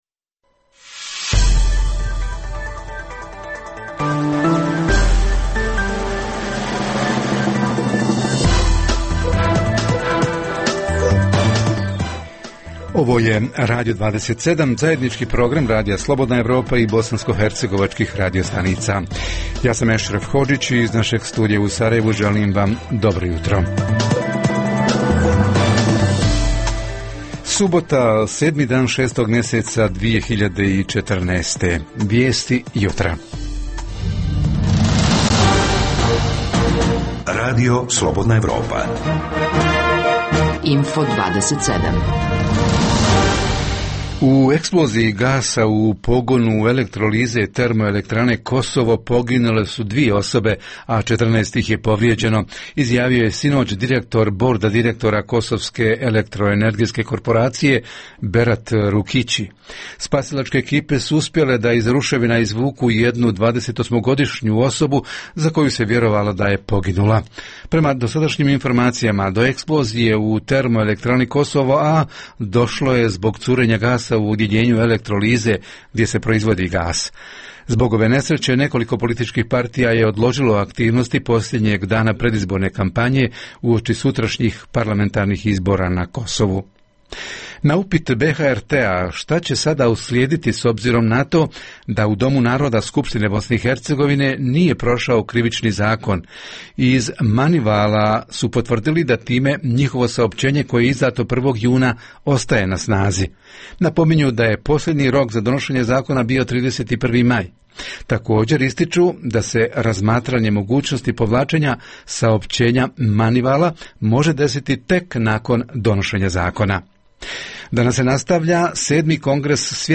- Naš reporter izravno iz Brčkog: najaktuelnije informacije o stanju nakon poplava u Posavini.